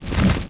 wings1.wav